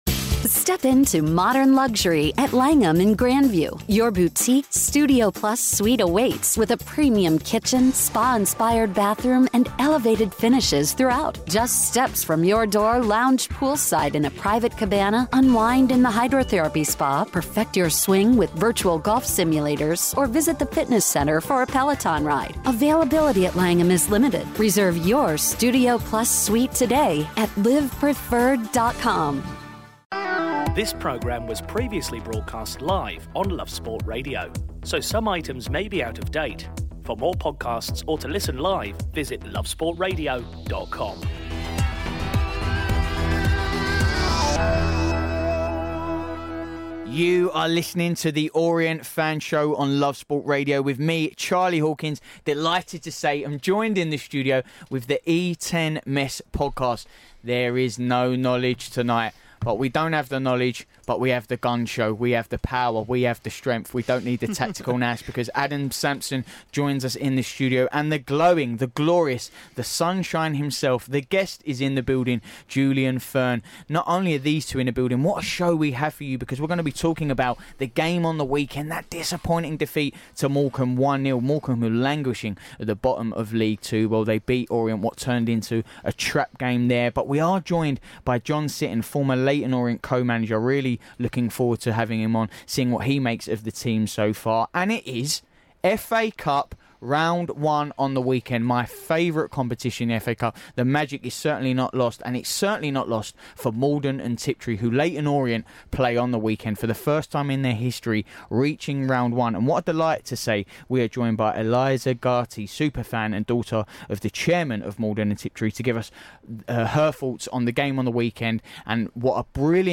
The boys from the E10Mess podcast dissect a poor run of form